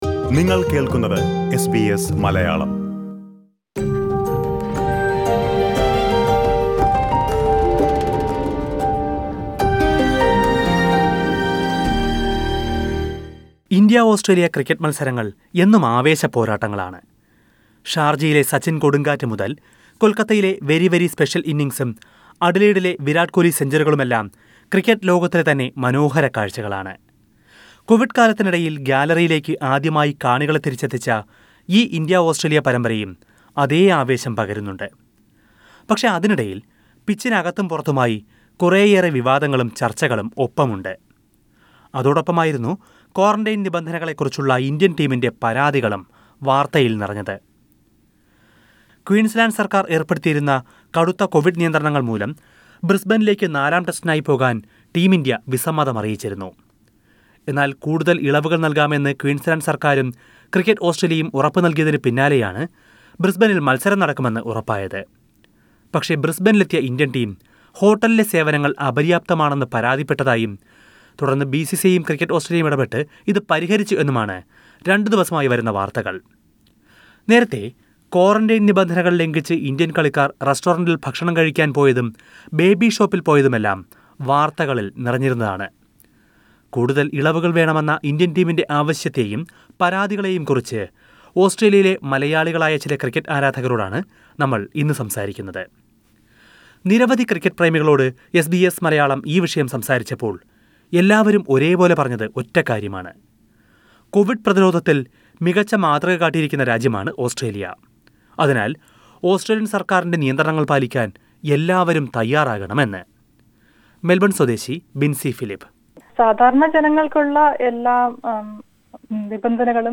SBS Malayalam spoke to cricket enthusiasts in Australia…